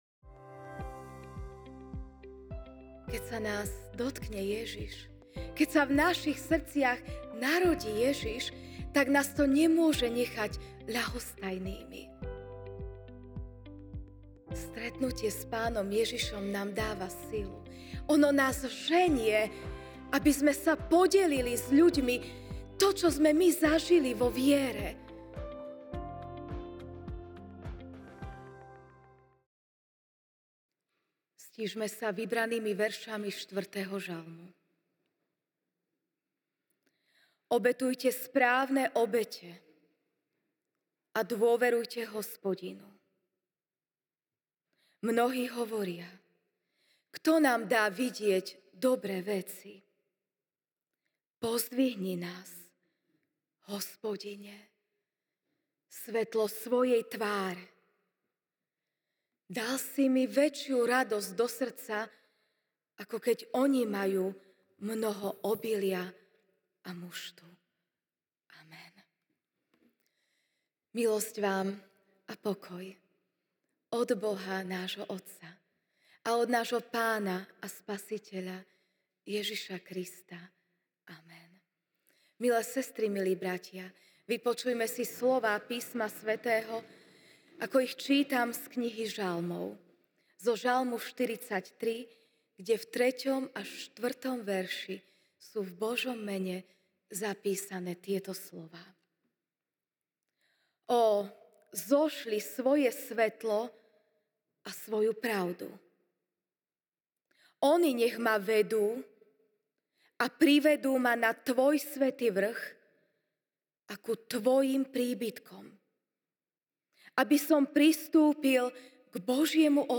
dec 25, 2024 Nasledovanie Svetla MP3 SUBSCRIBE on iTunes(Podcast) Notes Sermons in this Series Ranná kázeň: Ž(43, 3-4) „ Ó, zošli svoje svetlo a svoju pravdu!